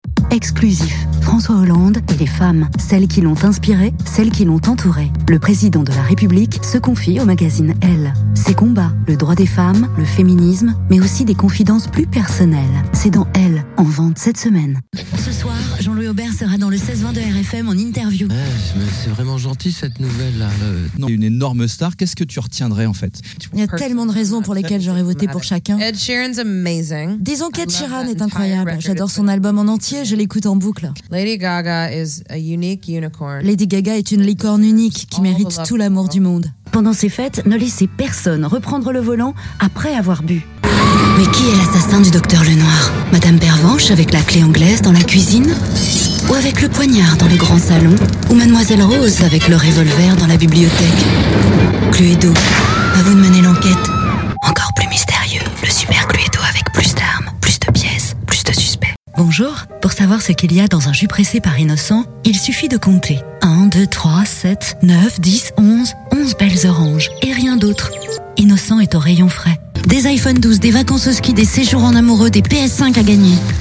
Voix off
bande démo
16 - 45 ans